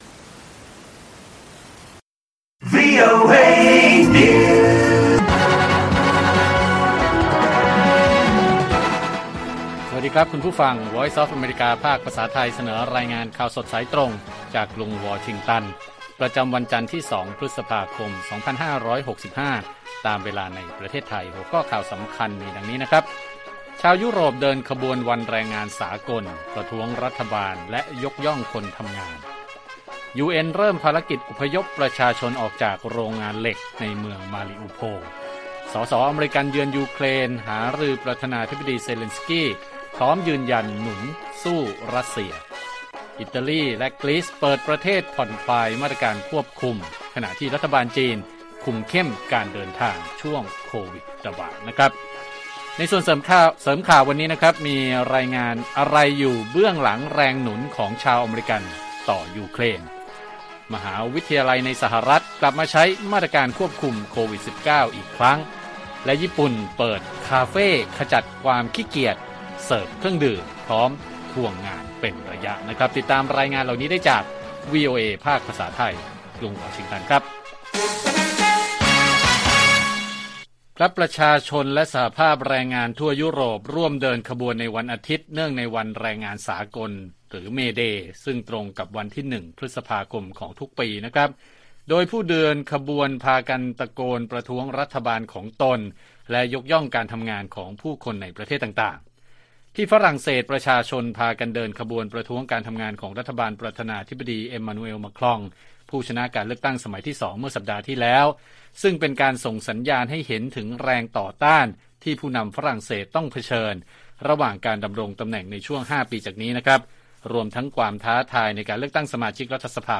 ข่าวสดสายตรงจากวีโอเอไทย 6:30 – 7:00 น. วันที่ 2 พ.ค. 65